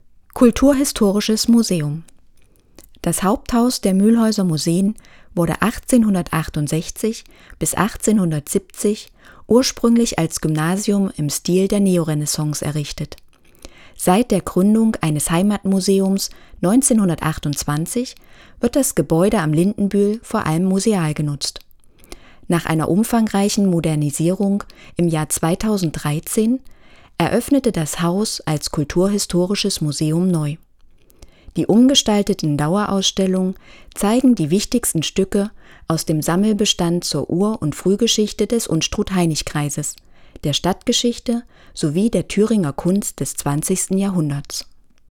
schema:keywords hörfassung